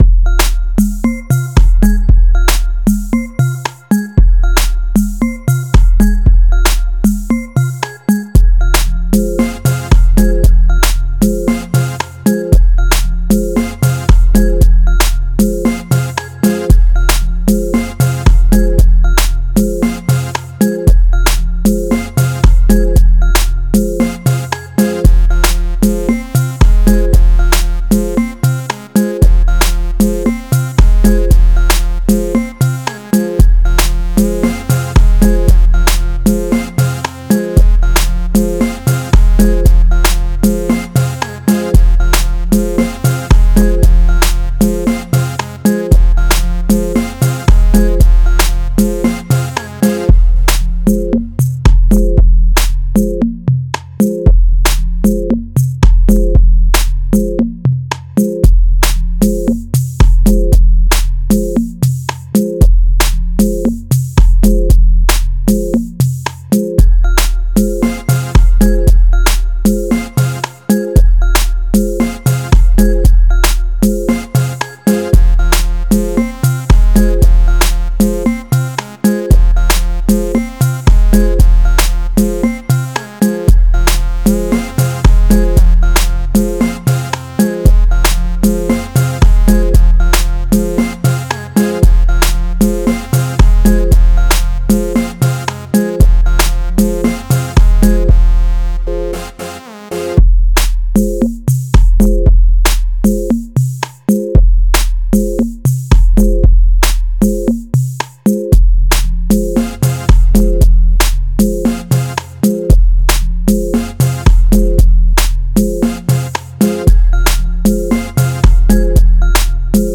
releases a new riddim for artists as new year gift.